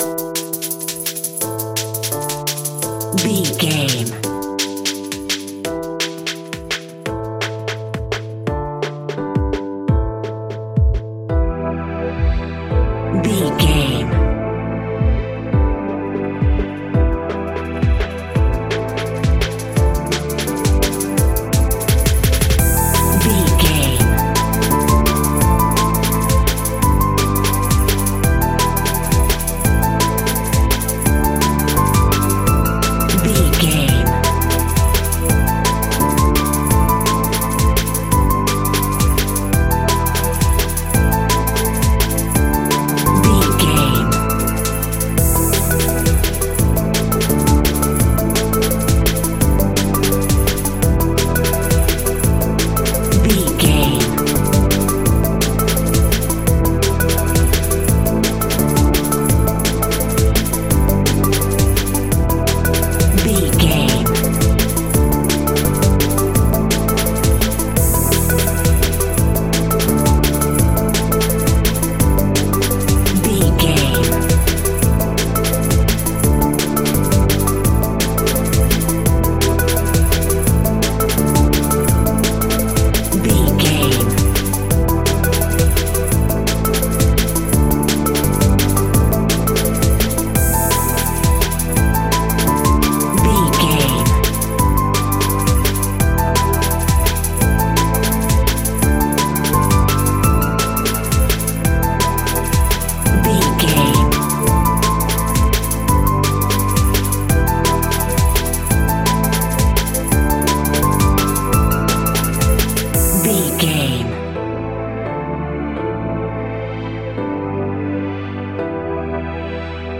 Aeolian/Minor
Fast
frantic
driving
energetic
hypnotic
industrial
dark
drum machine
electric piano
synthesiser
sub bass
synth leads